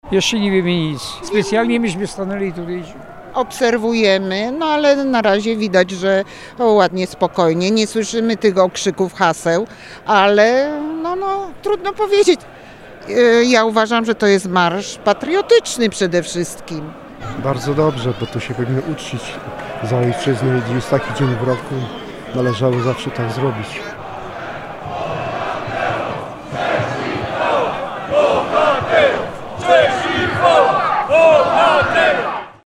05_Rozmowa-z-przechodniami-co-mysla-o-Marszu.mp3